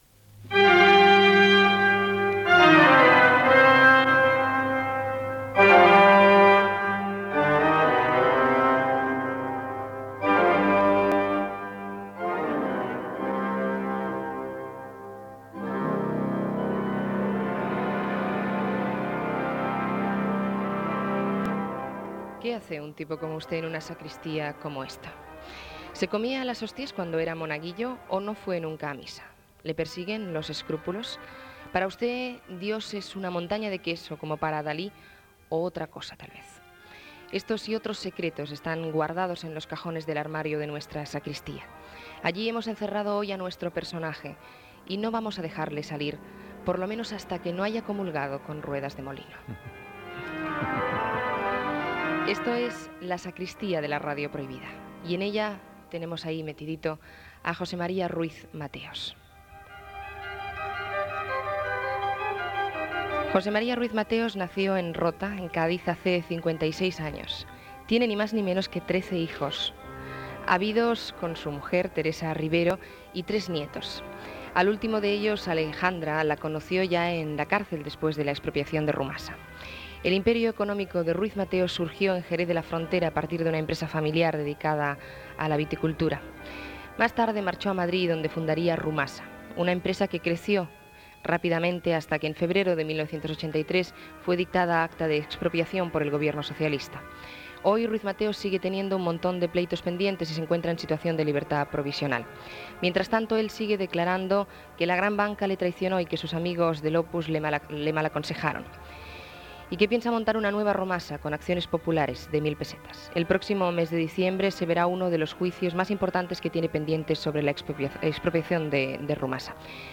"La sacristía" entrevista a l'empresari membre de l'Opus Dei José María Ruiz Mateos, que havia estat al capdavant de Rumasa fins que va ser expropiada pel goven espanyol l'any 1983 i que estava pendent de judici Gènere radiofònic Entreteniment